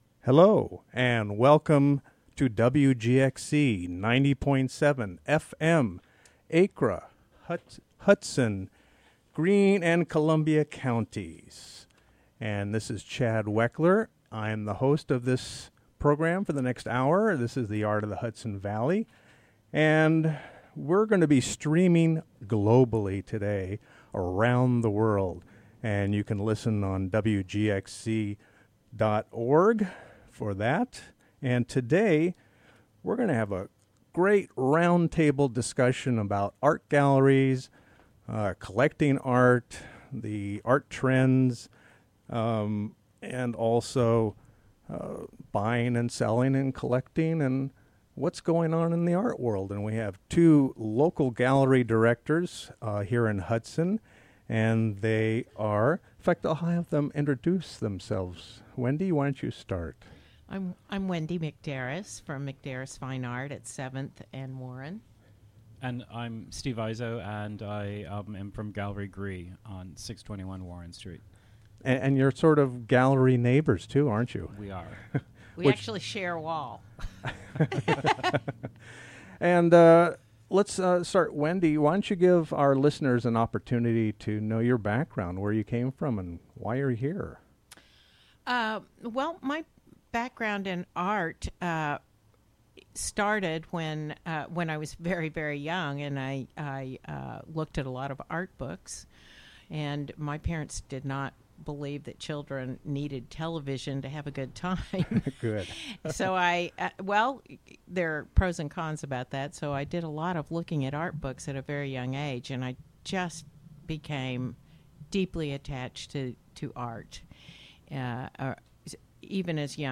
A discussion about art galleries, buying and selli...